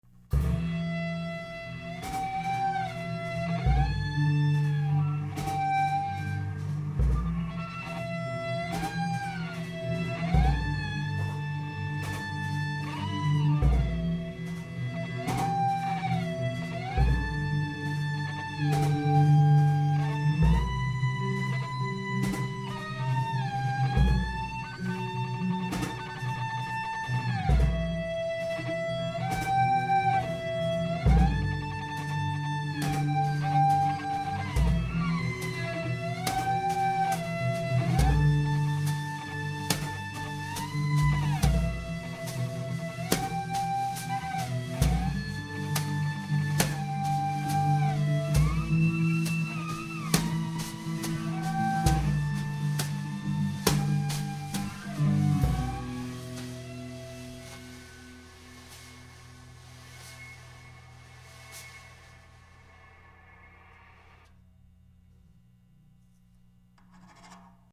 guitar, drums, cello.